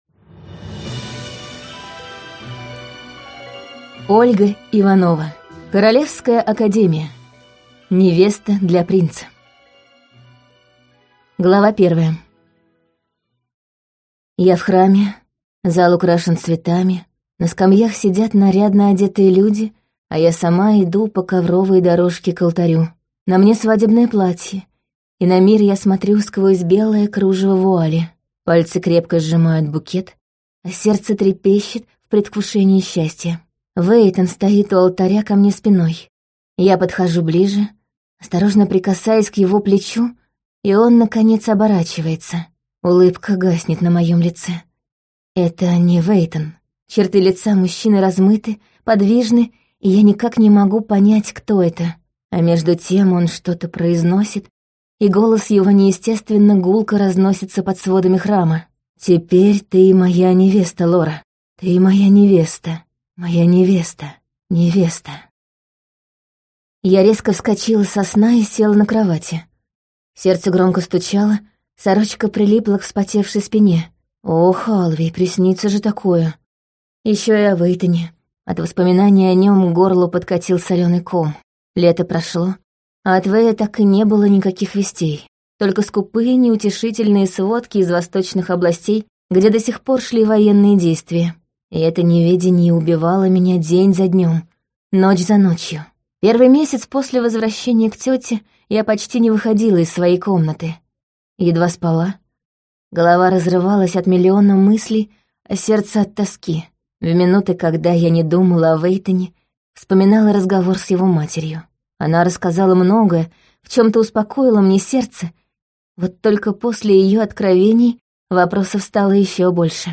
Аудиокнига Королевская Академия. Невеста для принца | Библиотека аудиокниг